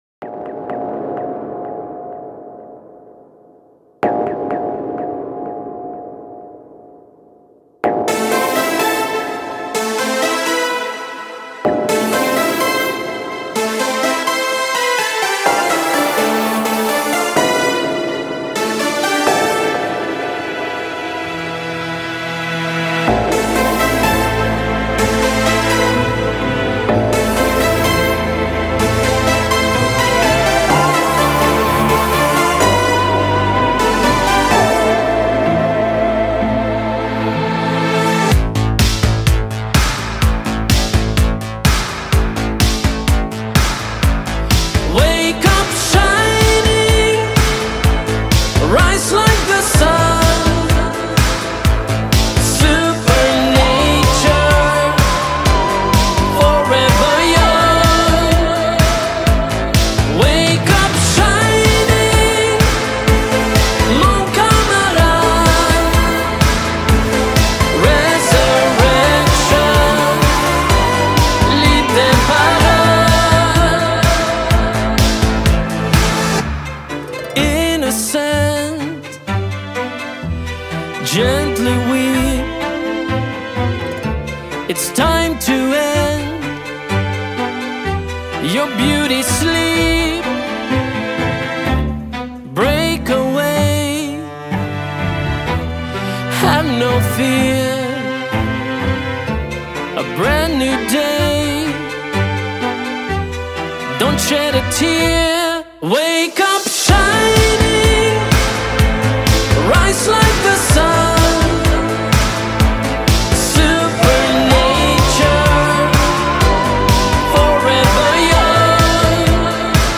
Легендарная шведская группа 80 - 90x.